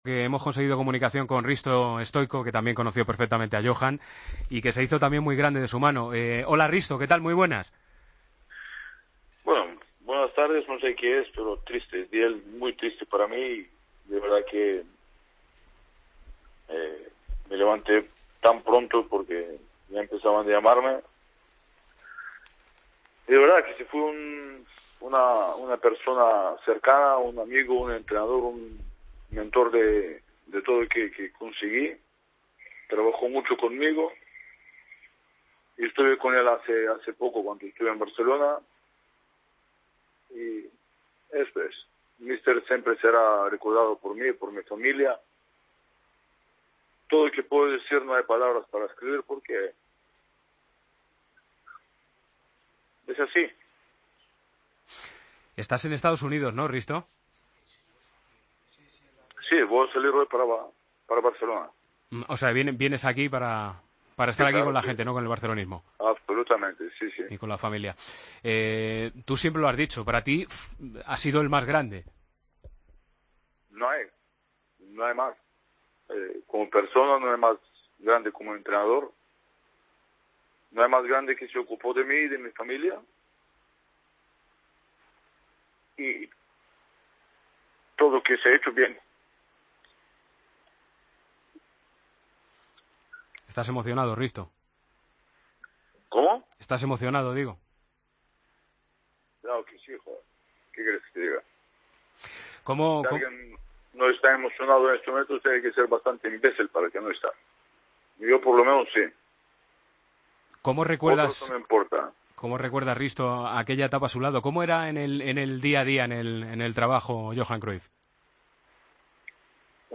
El búlgaro, bastante emocionado, le recordó en Deportes COPE como "el más grande de la historia" y le agradeció todo lo que hizo por él.